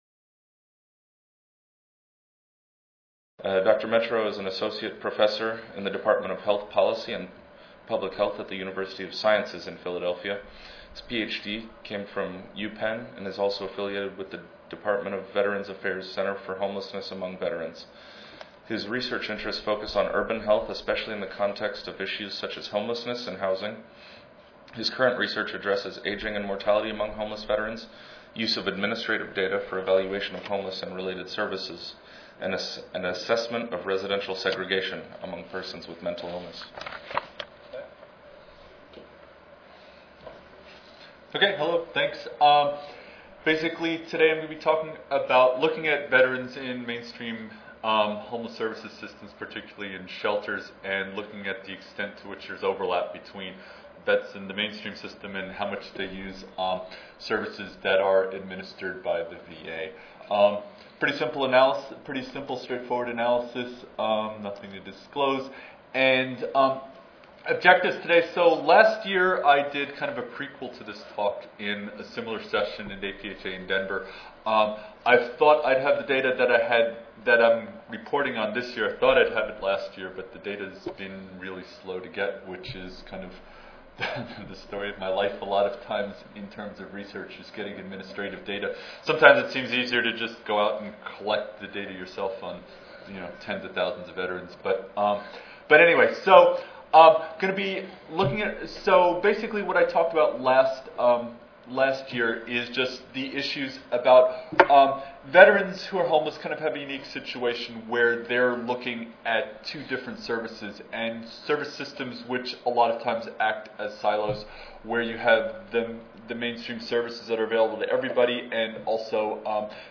4189.0 Prevention and Intervention Efforts to Reduce Homelessness Among Veterans Tuesday, November 1, 2011: 12:30 PM Oral Session Objectives: Define homelessness among Veterans and list services available to them.